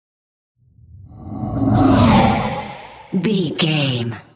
Whoosh monster breath
Sound Effects
Atonal
scary
ominous
eerie